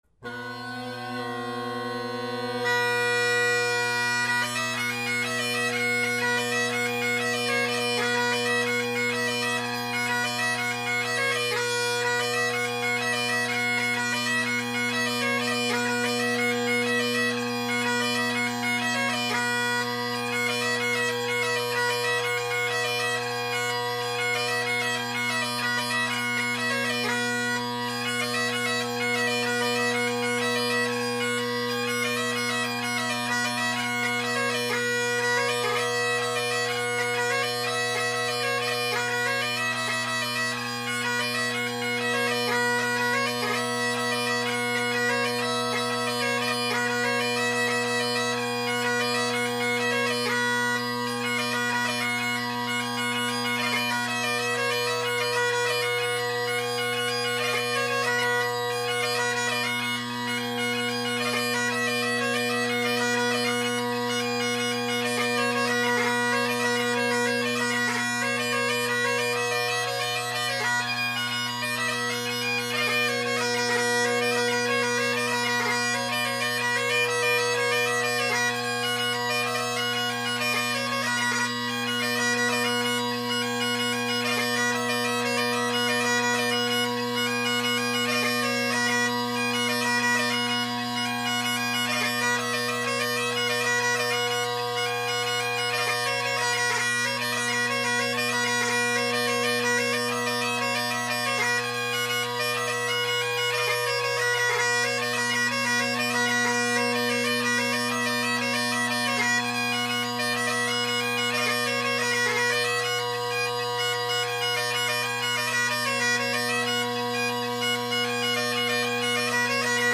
Drone Sounds of the GHB, Great Highland Bagpipe Solo
I hope you enjoy listening in on my jams: